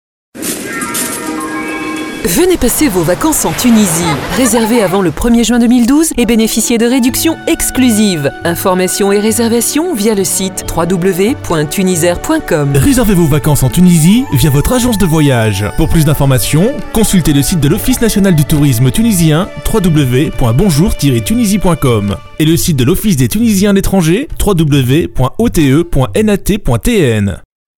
Pymprod réalise pour vous des spots publicitaires avec nos voix off masculines et féminines.
Pymprod réalise un spot aéroportuaire pour Tunisair.